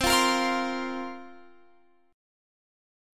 Listen to C7sus4 strummed